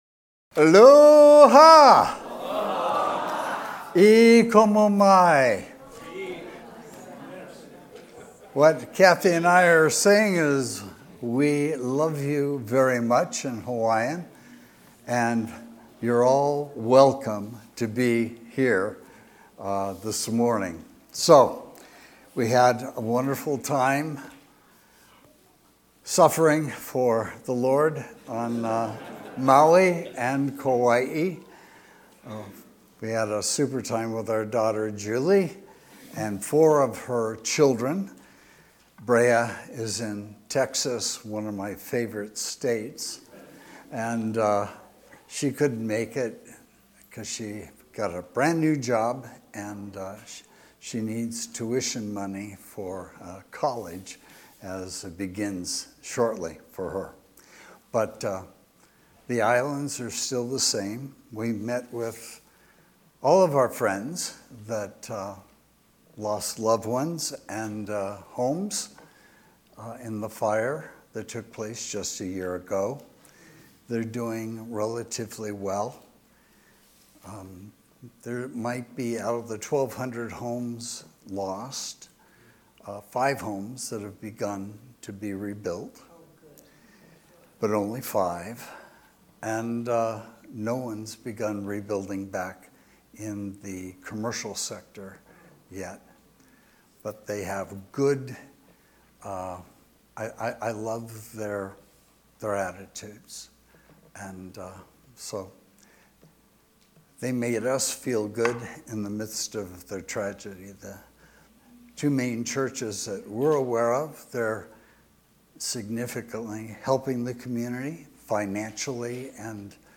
Sermons – Page 4 – Granada Hills Community Church